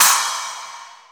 • Crash Single Shot C# Key 05.wav
Royality free crash cymbal sample tuned to the C# note. Loudest frequency: 5705Hz
crash-single-shot-c-sharp-key-05-va1.wav